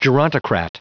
Prononciation du mot gerontocrat en anglais (fichier audio)